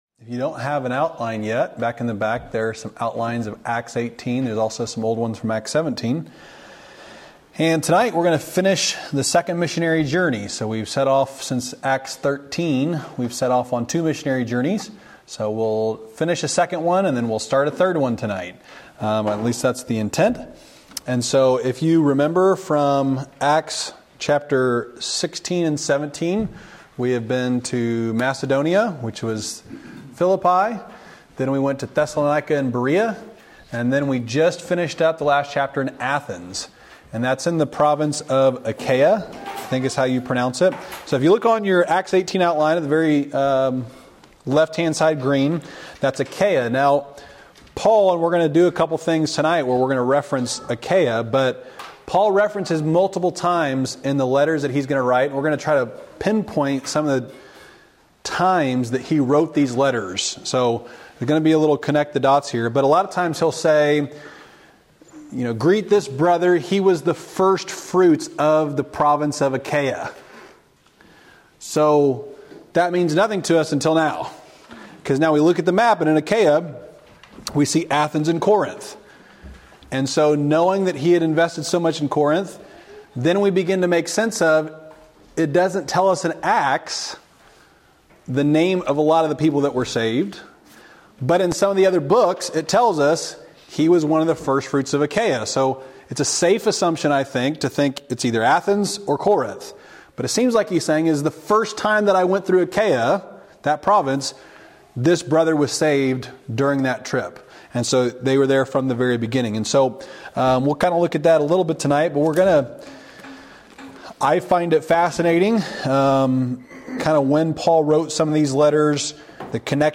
Wednesday night lesson from April 10, 2024 at Old Union Missionary Baptist Church in Bowling Green, Kentucky.